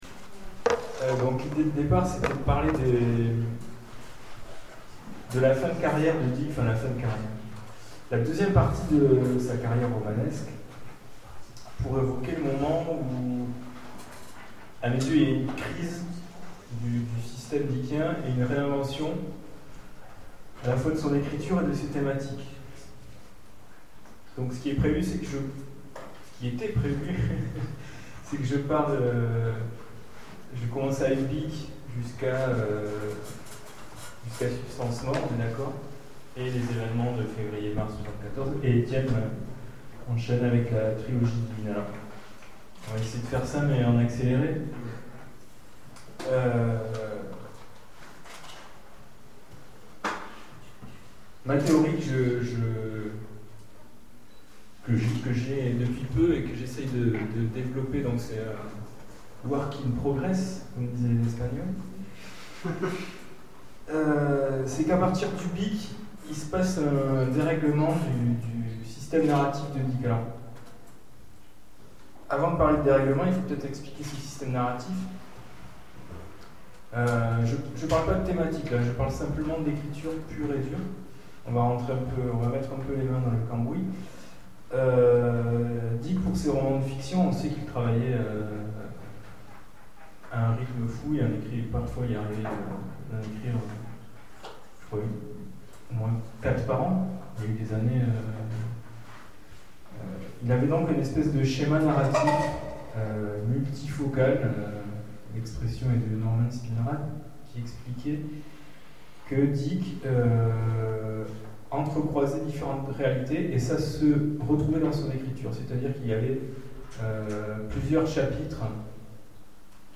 Philip K.Dick, trente ans après... Conférence De Ubik à la Trilogie Divine : Philip K. Dick au-delà de la SF